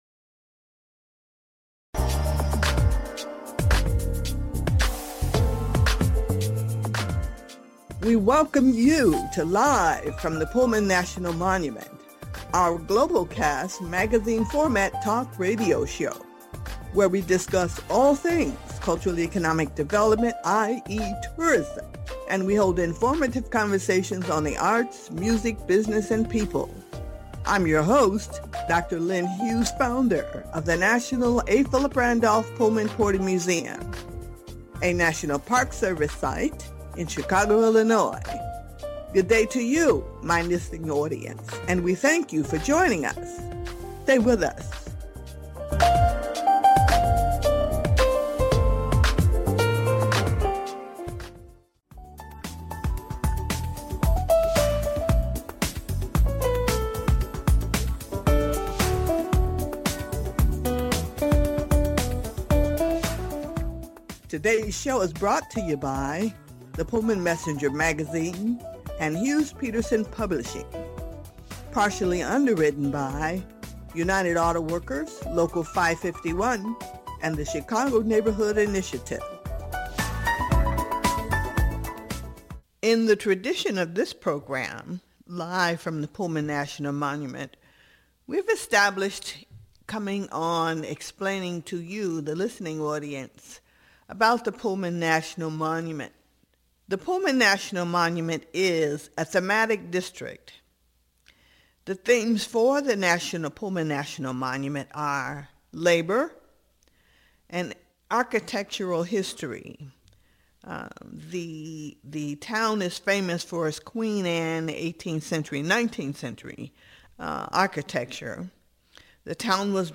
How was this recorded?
Live From Pullman National Monument